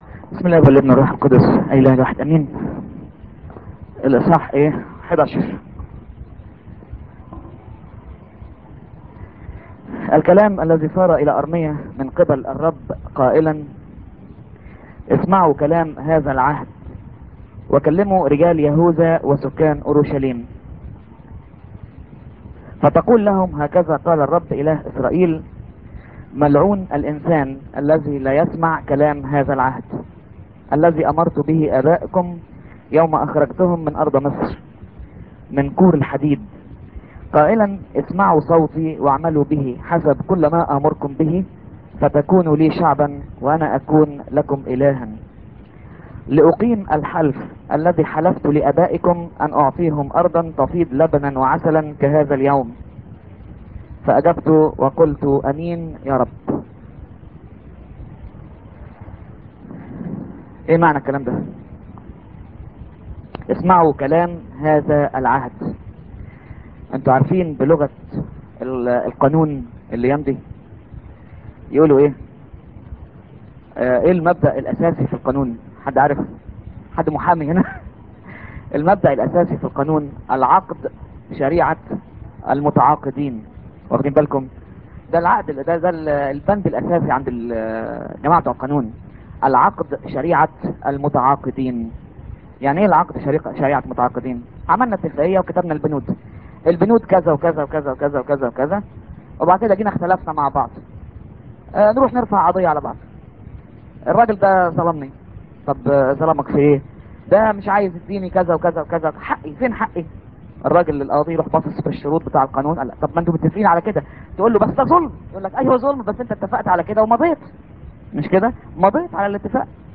St. Mary Church, El Fagallah, Cairo.